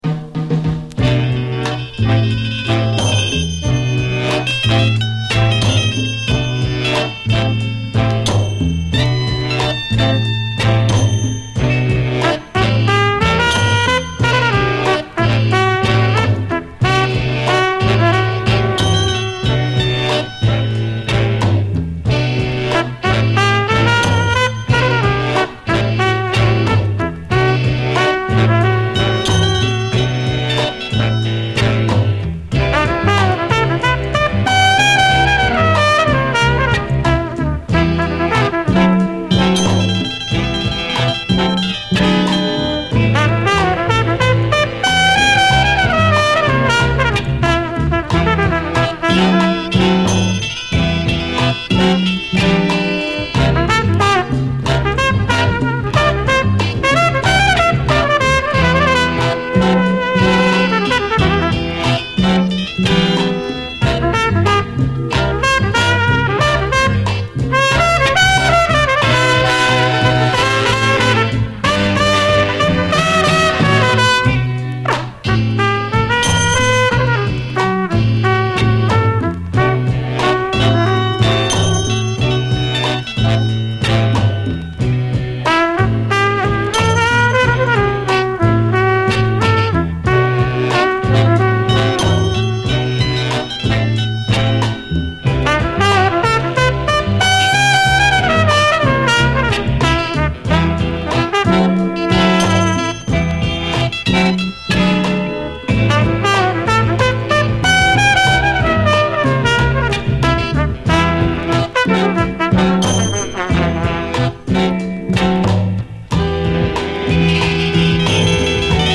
鋭いレアグルーヴ感で切り裂くスリリング・モダン・ソウル
トロピカルなブレイクからレゲエ調な展開を挟むというなかなか珍しいタイプの曲で当店好みなミディアム・ソウル